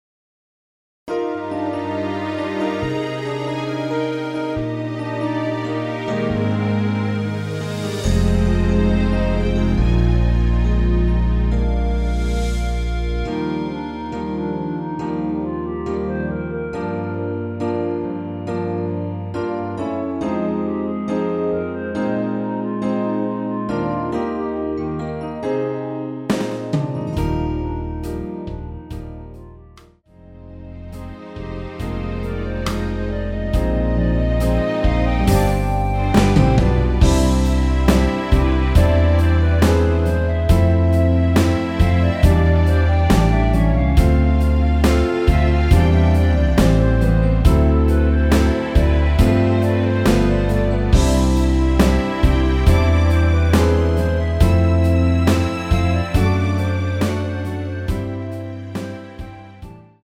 Eb
멜로디 MR이라고 합니다.
앞부분30초, 뒷부분30초씩 편집해서 올려 드리고 있습니다.
중간에 음이 끈어지고 다시 나오는 이유는